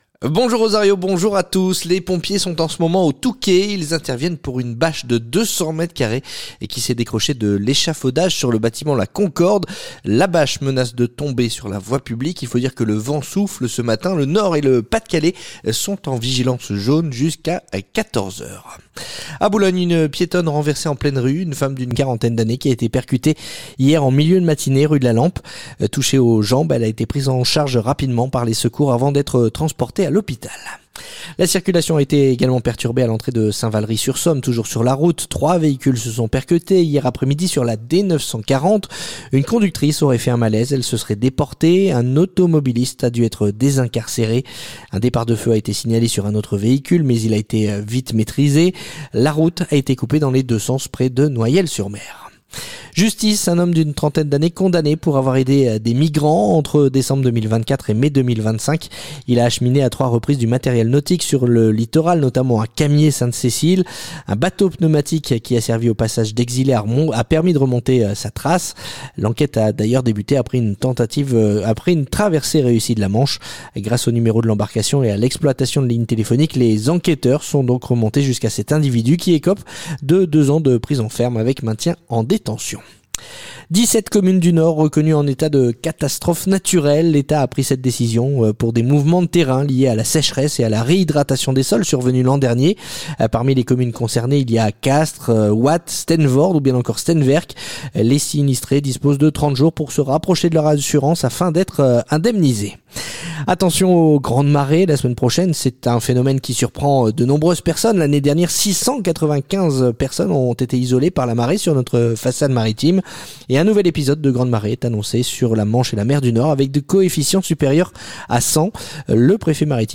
Le journal du samedi 28 février